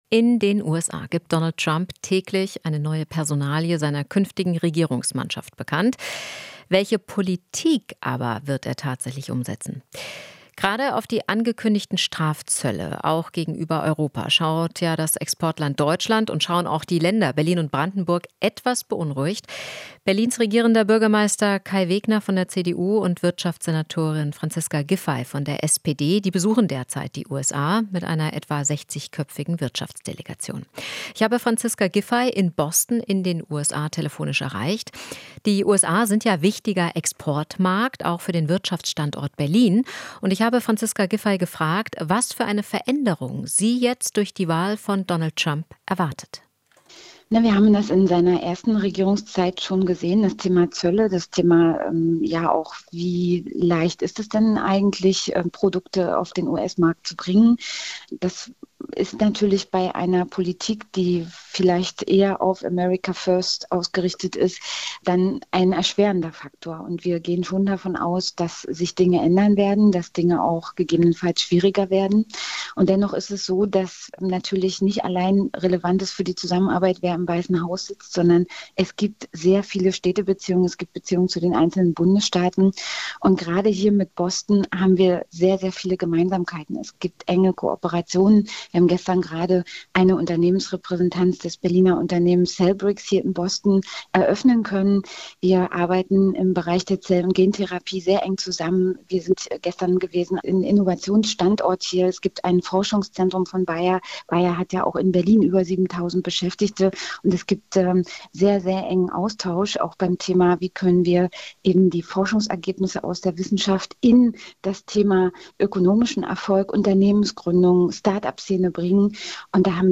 Interview - Giffey (SPD): "Wollen Innovationsstandort Nummer eins werden"
Aus Boston erklärt sie, was sie sich für Berlin von einer wirtschaftlichen Zusammenarbeit mit Partnern in den USA verspricht.